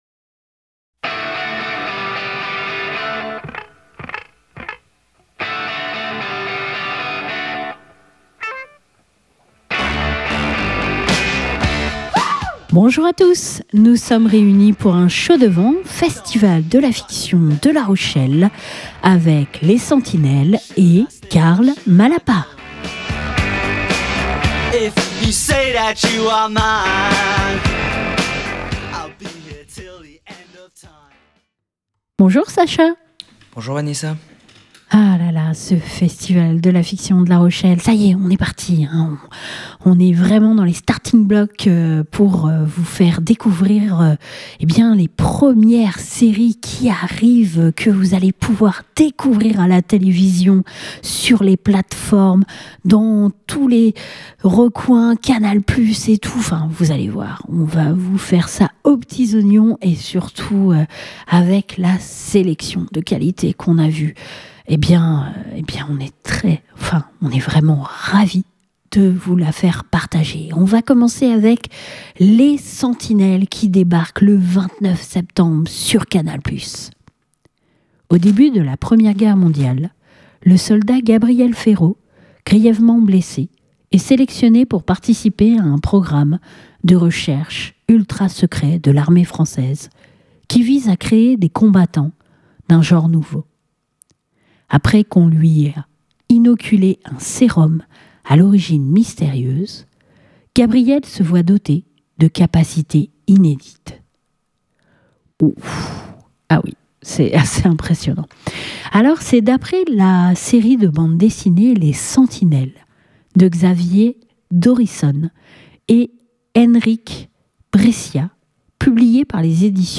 Ce dernier nous a accordé une entrevue pour revenir sur ce Captain America à la française et plus humain.